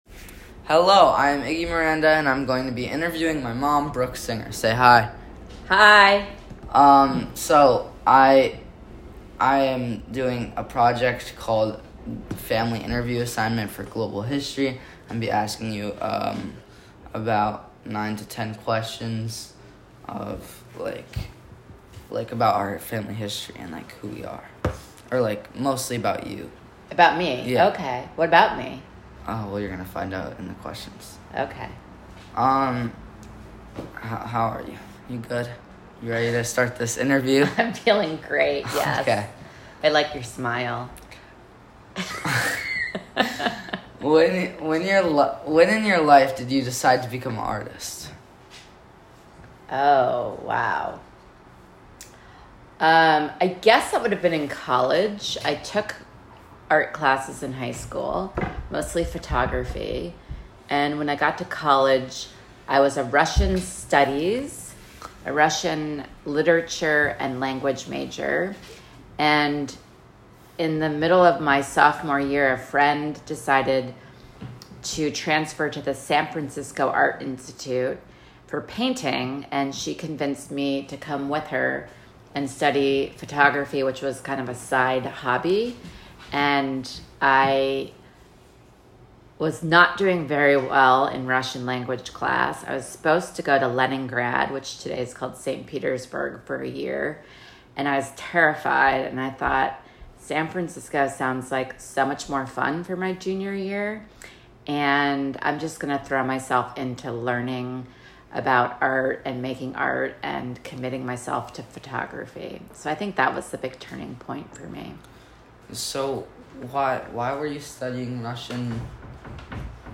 Why I became an artist , interview